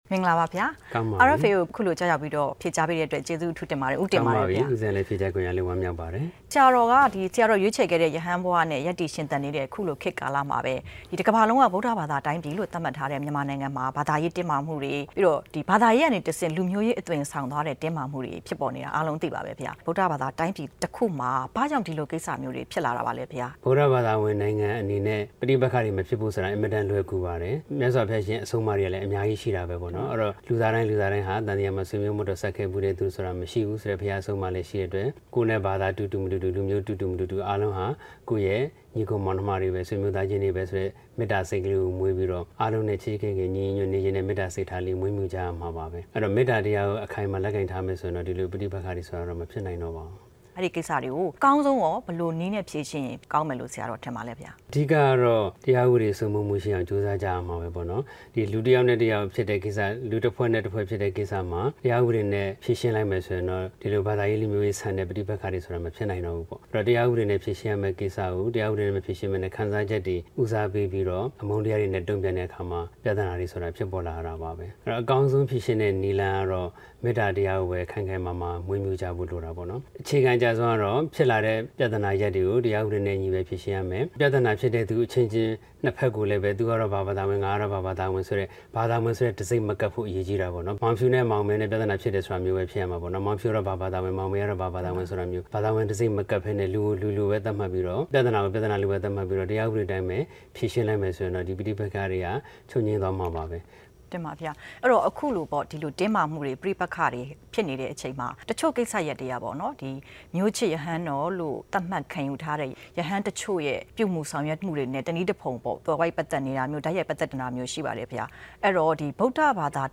တွေ့ဆုံခြင်း
ဝါရှင်တန်ဒီစီက RFA ရုံးချုပ်မှာ